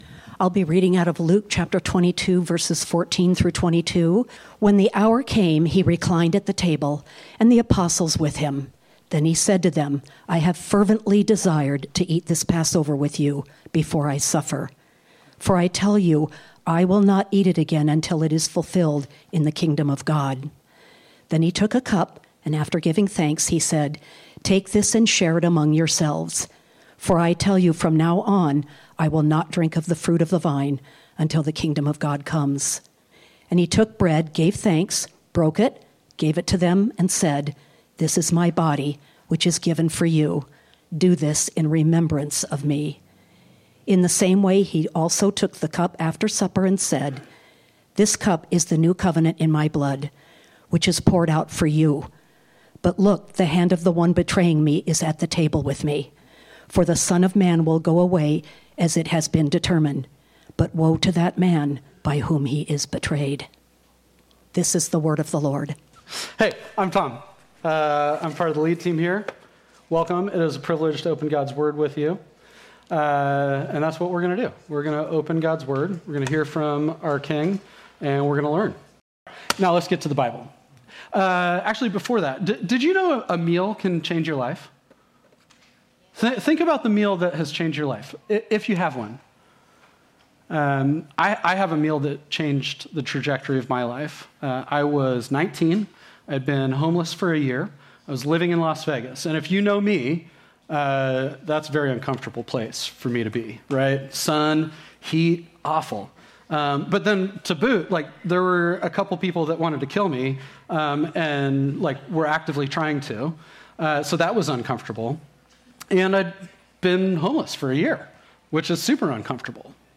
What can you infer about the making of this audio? This sermon was originally preached on Sunday, June 14, 2020.